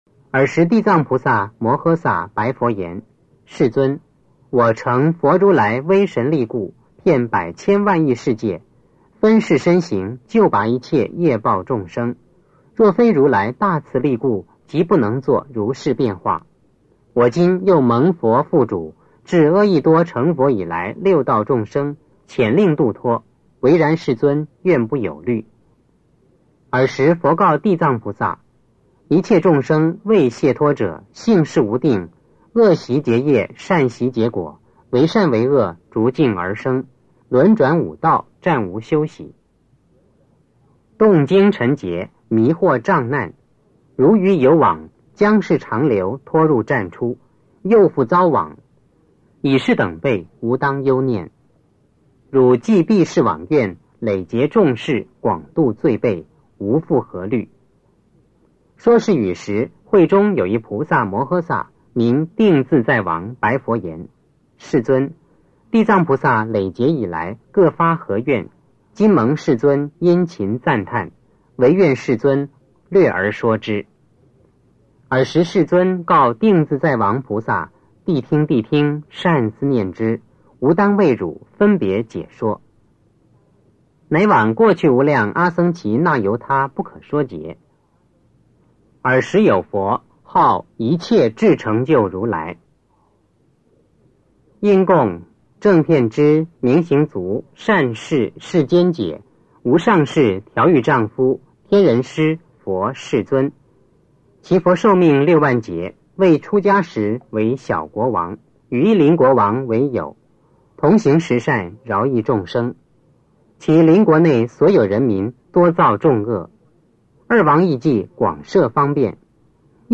地藏经(男声念诵）4 - 诵经 - 云佛论坛
地藏经(男声念诵）4